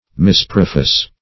Search Result for " misprofess" : The Collaborative International Dictionary of English v.0.48: Misprofess \Mis`pro*fess"\, v. i. To make a false profession; to make pretensions to skill which is not possessed.